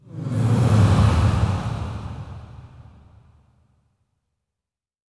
SIGHS 3   -L.wav